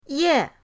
ye4.mp3